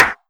drum-hitnormal.wav